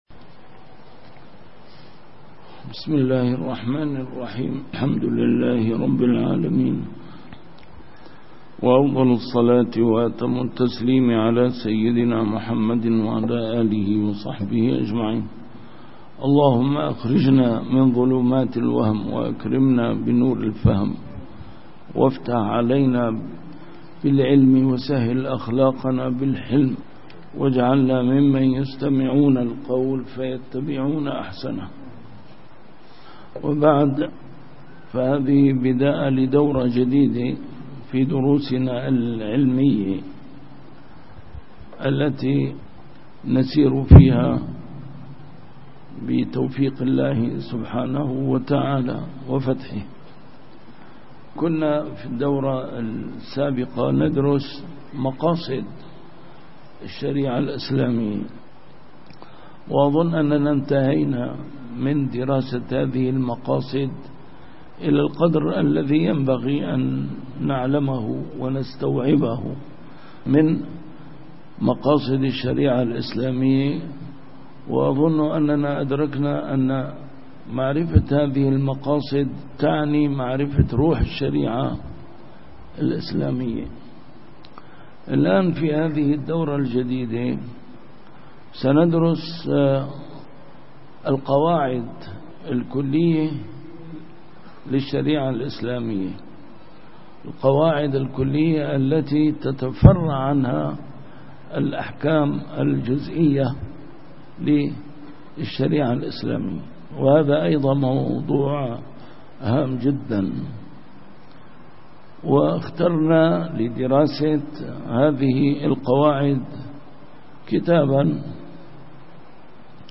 A MARTYR SCHOLAR: IMAM MUHAMMAD SAEED RAMADAN AL-BOUTI - الدروس العلمية - كتاب الأشباه والنظائر للإمام السيوطي - كتاب الأشباه والنظائر، الدرس الأول: شرح القواعد الخمس التي ذكر الأصحاب أن جميع مسائل الفقه ترجع إليه